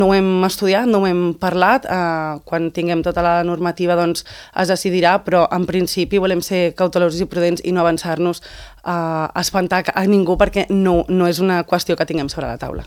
Ho ha explicat a l’espai ENTREVISTA POLÍTICA de Ràdio Calella TV, on s’ha alineat amb el posicionament de Buch respecte a la necessitat de lleis més dures per combatre la multireincidència.